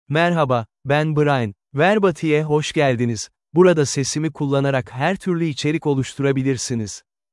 MaleTurkish (Turkey)
BrianMale Turkish AI voice
Brian is a male AI voice for Turkish (Turkey).
Voice sample
Brian delivers clear pronunciation with authentic Turkey Turkish intonation, making your content sound professionally produced.